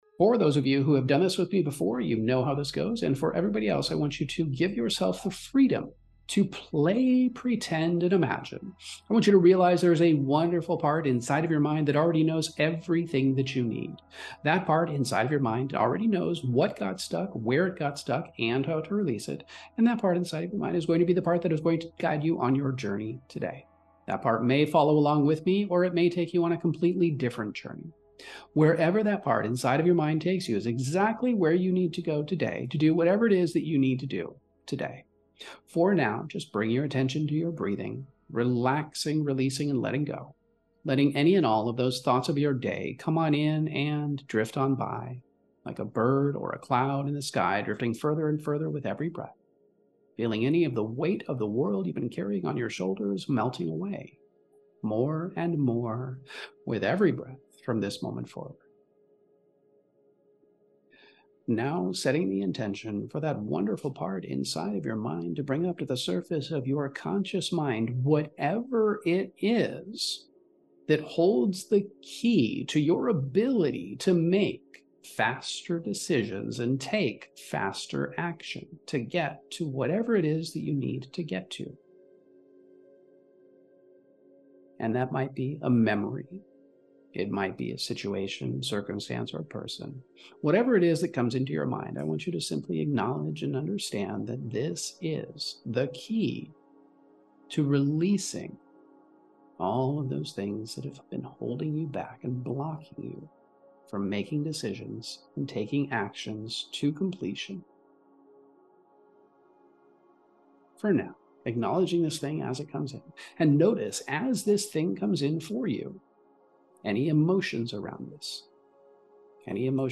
This guided hypnosis meditation is like this cool mental journey that helps you chill out and then digs deep to find out why making decisions feels like such a struggle. It's like it's got this way of making you face the stuff that freaks you out—fear, doubt, you name it—and then teaches you how to blast through it like it's nothing.